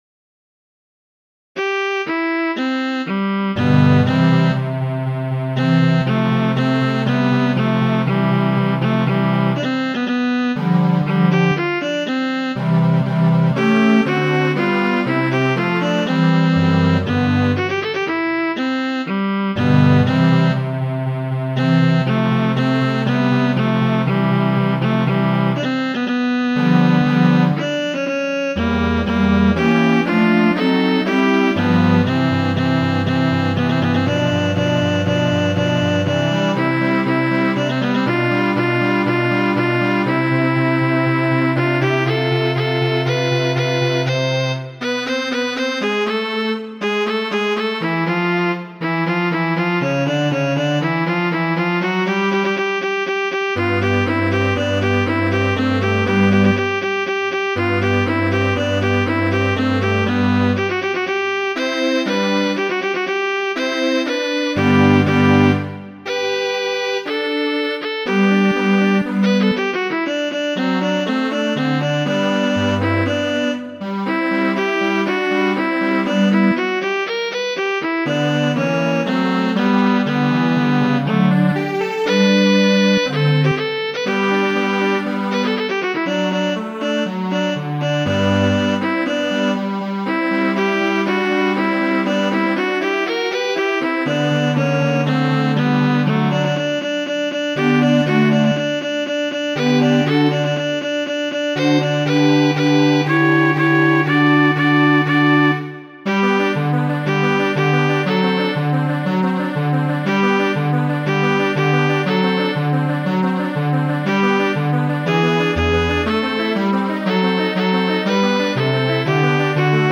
Sonata en do, verkita de Fernando Sor la lastan jarcenton..